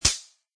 plasticmetal3.mp3